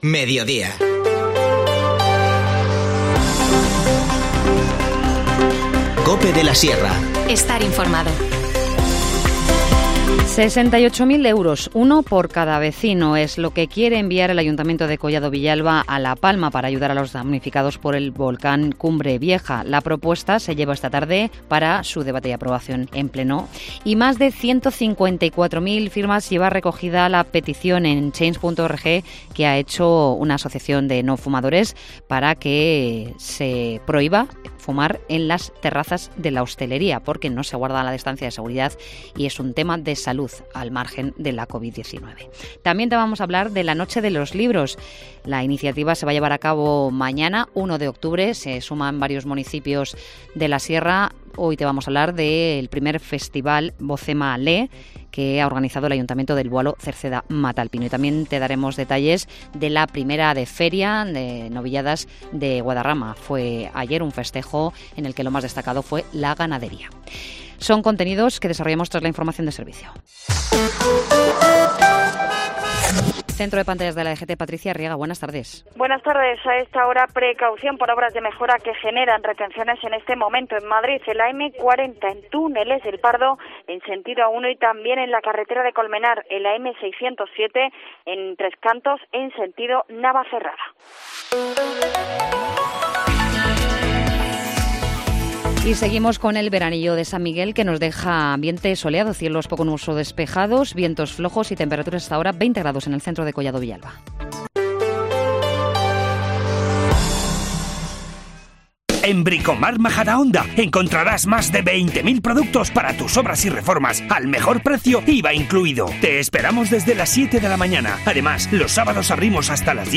Informativo Mediodía 30 septiembre